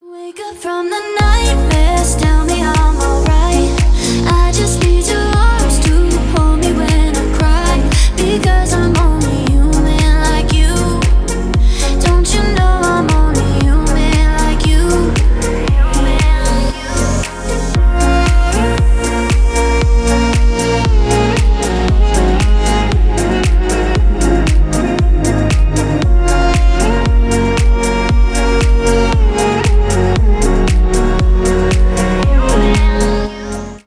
Поп Музыка
клубные # спокойные